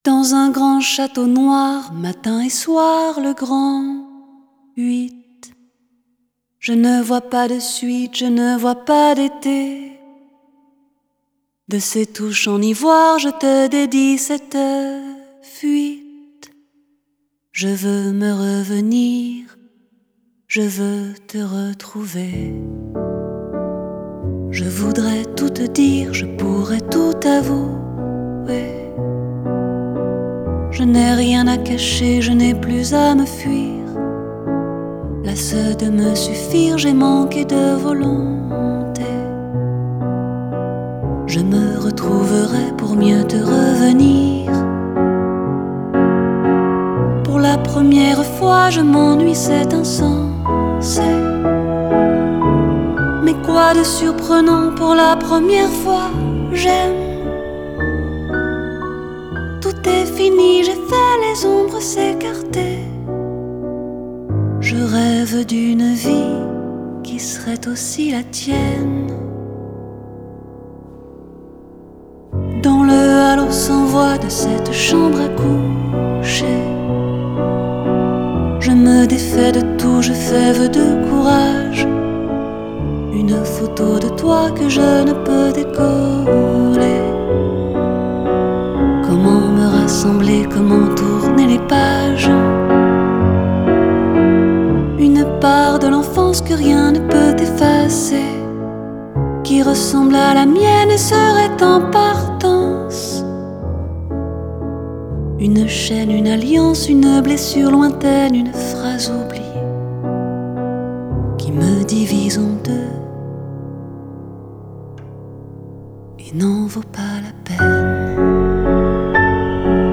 Genre: French Pop, Chanson